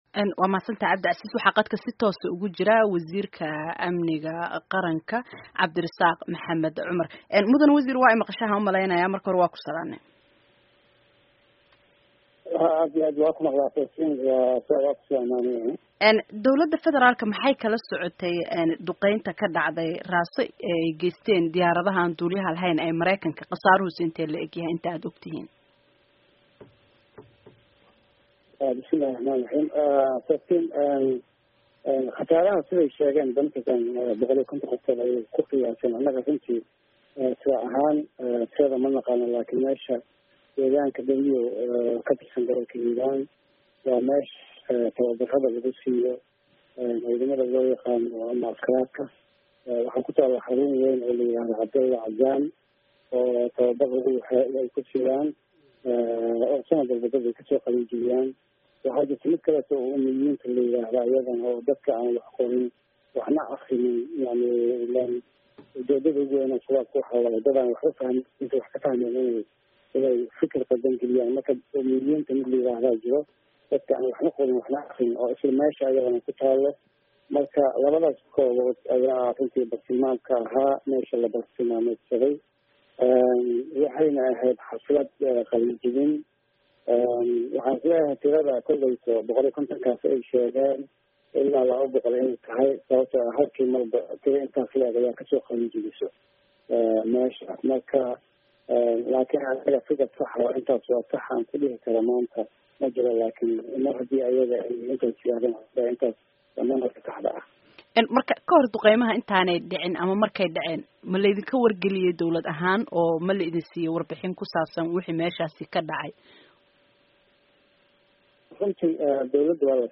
Wasiirka amniga qaranka Soomaaliya Cabdirisaaq Cumar Maxamed oo wareysi siiyey VOA-da ayaa sheegay in dowladda Soomaaliya ay ka war-heysay weerarka oo Mareykanka uu kusoo wargeliyey.